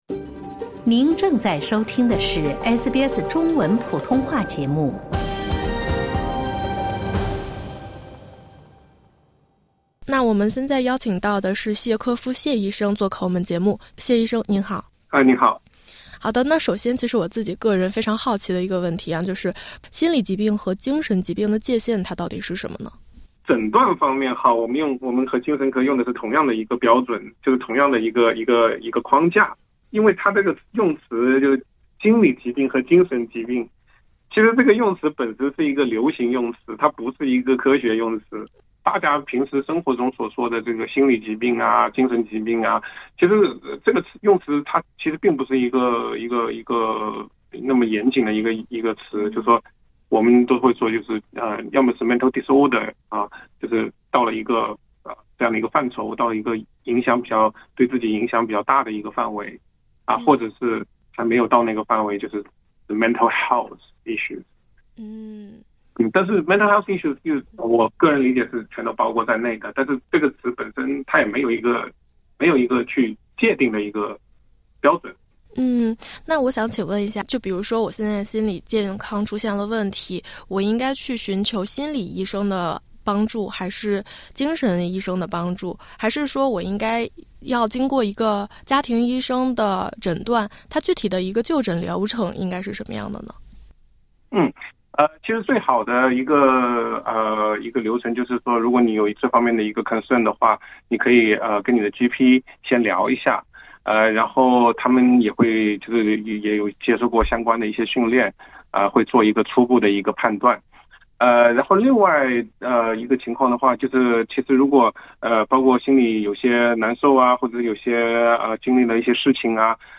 感知到哪些情绪其实是心理健康出现问题的信号？身体出现了哪些反应说明需要及时就医？（点击播客，收听采访）